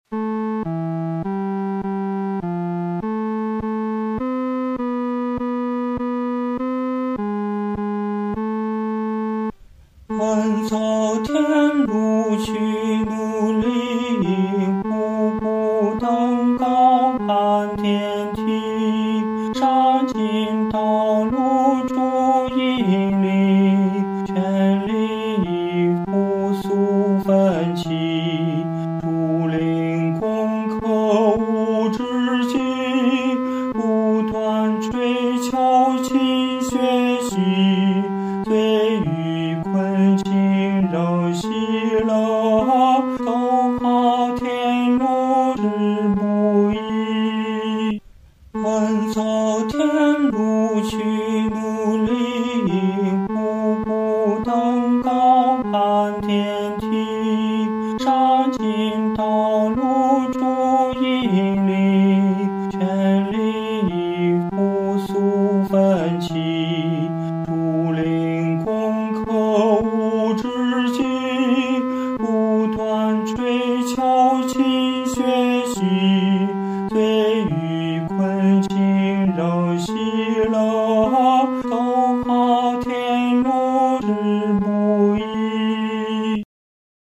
合唱
男高
曲调开始是商调，非常有力，接着转为羽调，作了一个肯定；然后又转为宫调，有一个稳定的半终止。
这首圣诗的弹唱速度不宜太拖沓。